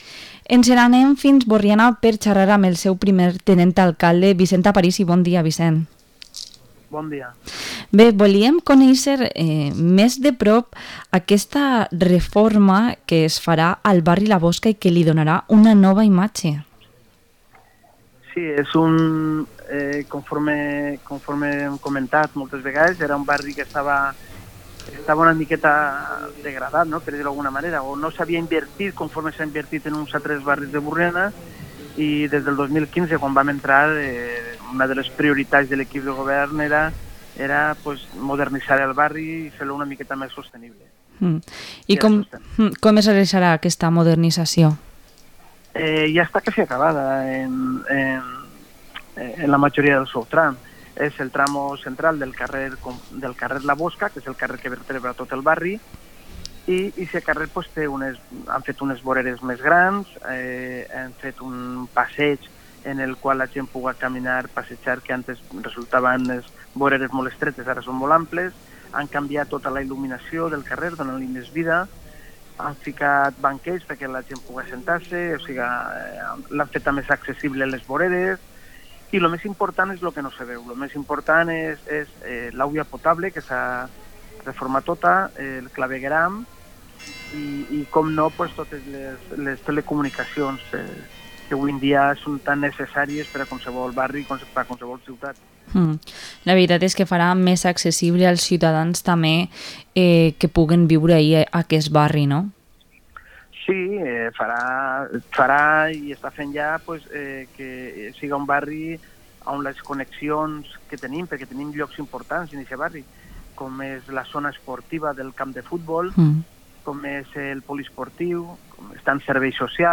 Entrevista al primer teniente alcalde de Burriana, Vicent Aparisi